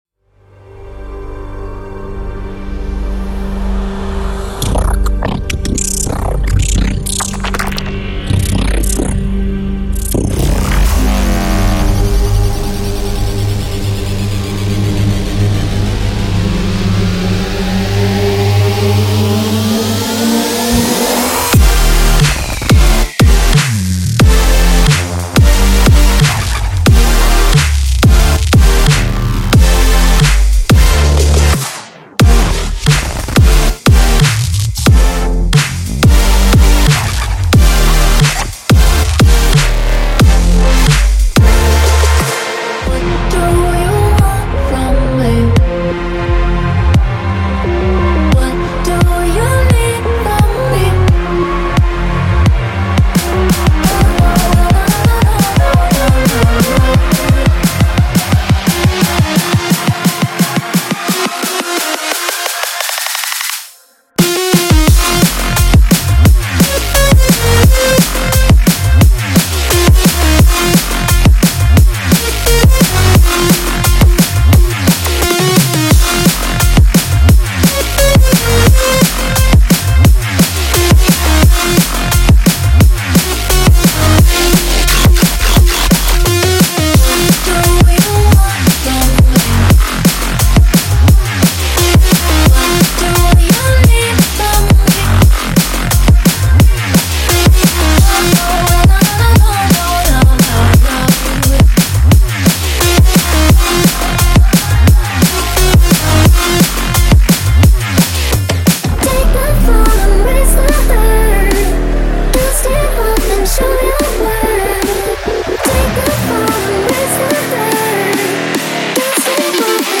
2025-12-31 Dubstep · EDM · House · Trap 266 推广
打击乐部分更是精彩纷呈，包含键控底鼓、键控军鼓和拍手声、原声打击乐、电子打击乐以及传统世界打击乐采样。
其强大的效果器库包含丰富的渐强、冲击、氛围音效以及数百种故障和短促的音效，为您的歌曲增添悦耳动听的元素。